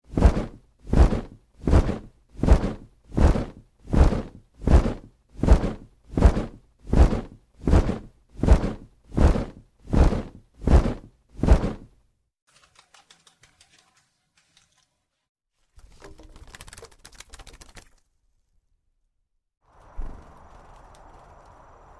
Сборник звуков взмахов крыльев ангелов и птиц